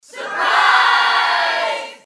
surpriseyell.mp3